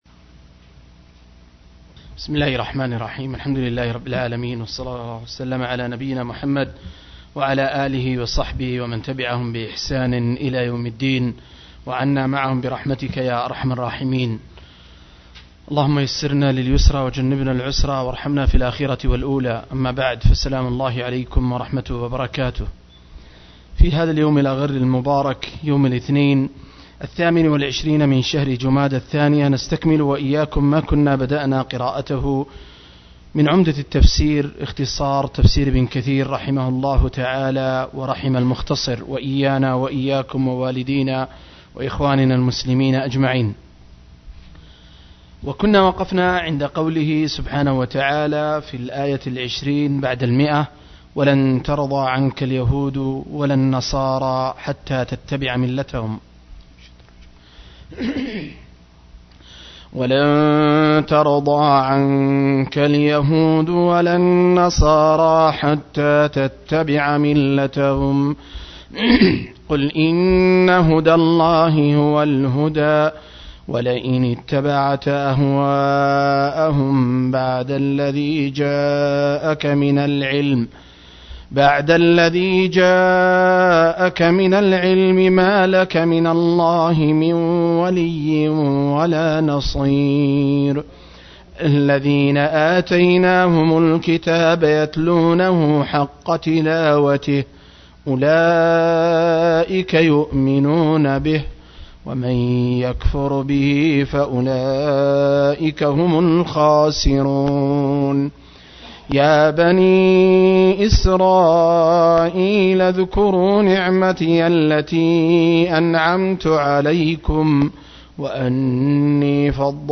المجلس السابع والعشرون: تفسير سورة البقرة (الآيات 120-125)